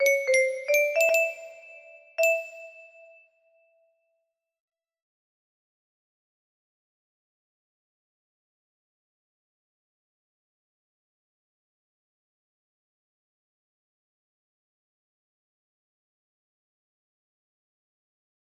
ko music box melody